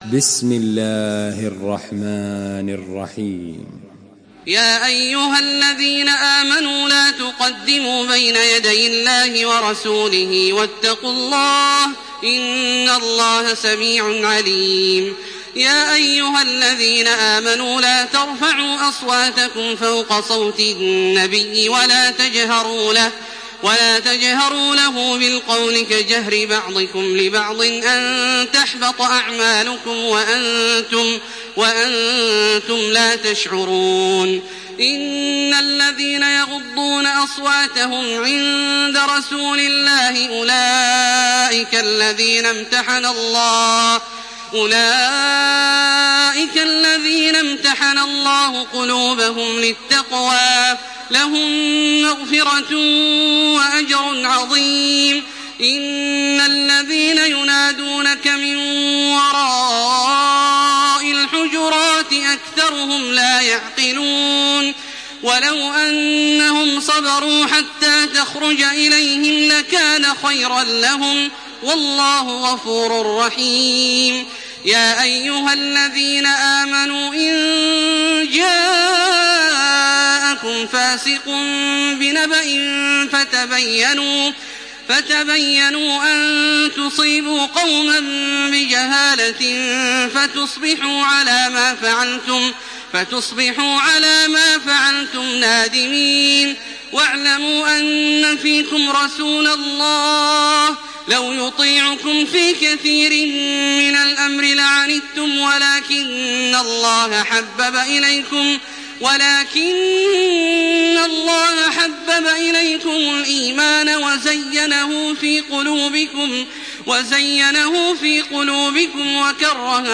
Surah Al-Hujurat MP3 by Makkah Taraweeh 1428 in Hafs An Asim narration.
Murattal Hafs An Asim